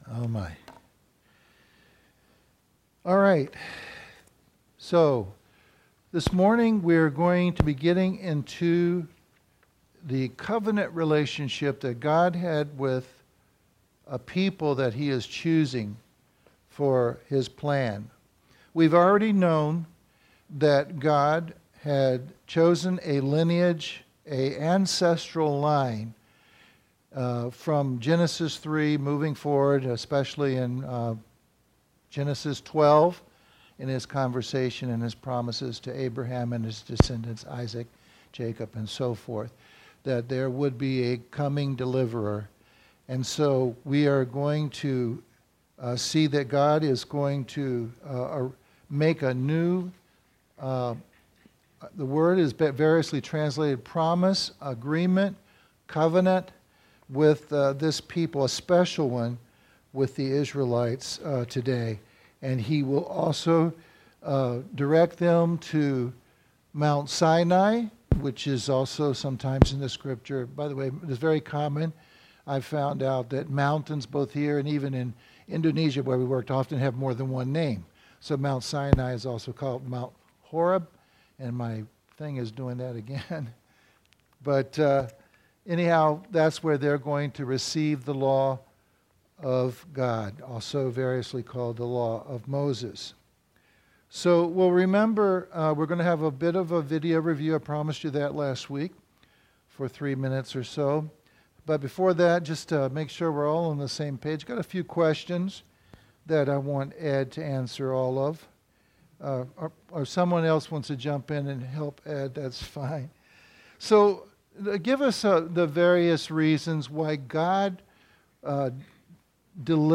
Firm Foundations – Lesson 22: God’s Covenant with Israel – Part 1